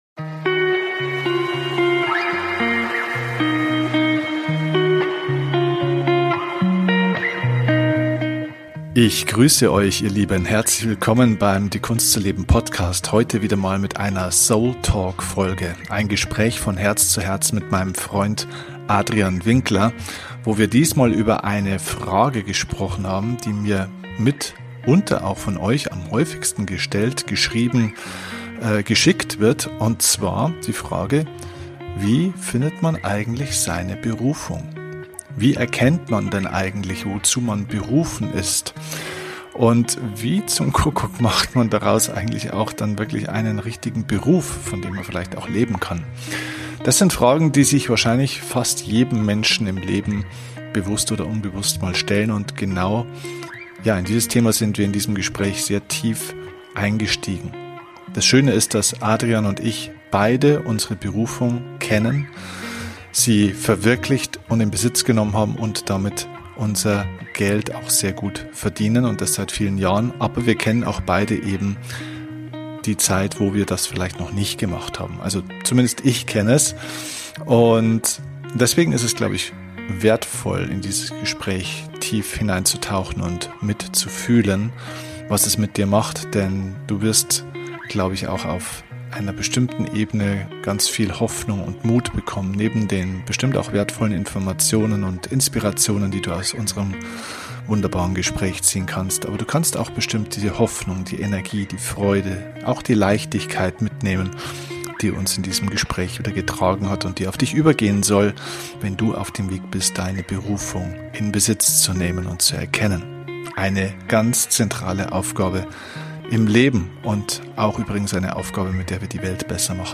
Wie immer bei diesem Format gibt es kein Skript.